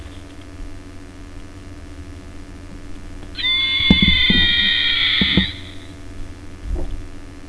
Action feature: (( DinoScreams )) & Slashing Claw.
here to hear it scream!
Activating this strike action also causes an nasty attack scream to be heard.
This sound, though not movie accurate, fits the figure well enough and is quite eerie and chilling. It sounds more like an unfortunate victim’s death scream but firmly establishes this Raptor as vicious hell spawn.